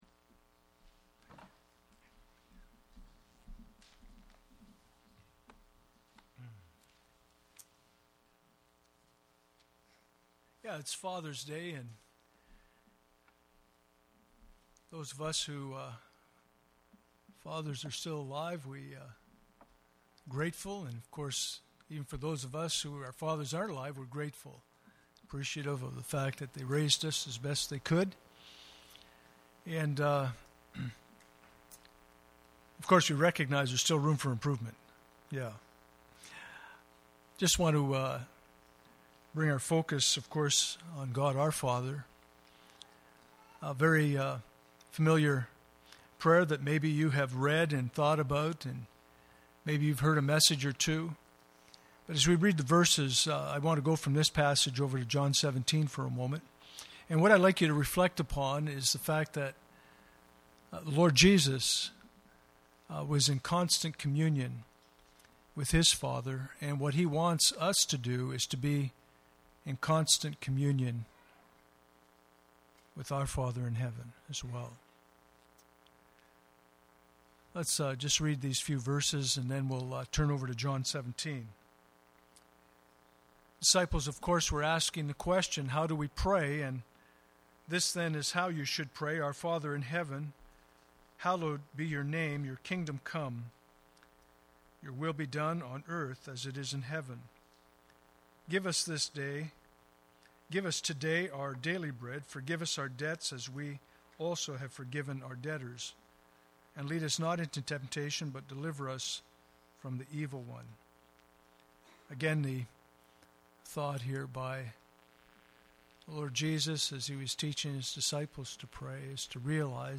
Communion Service